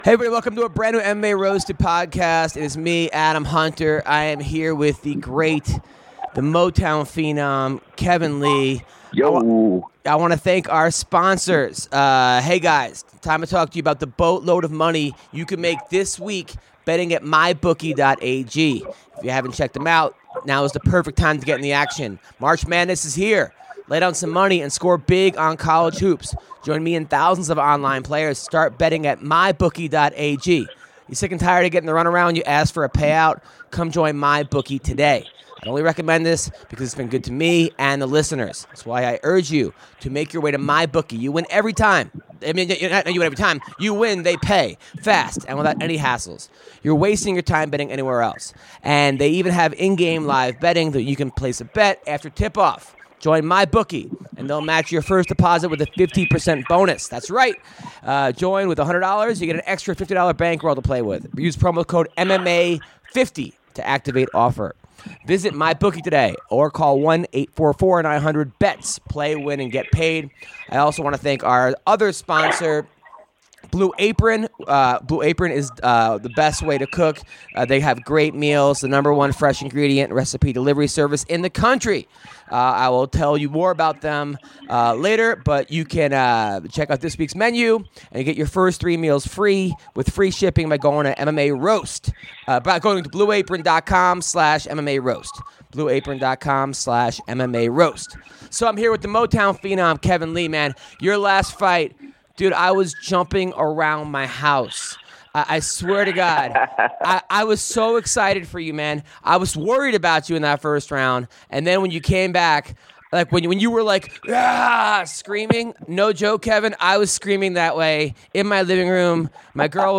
In this episode of the MMA Roasted Podcast, Kevin Lee calls in to discuss his recent victory as well as his future in the UFC.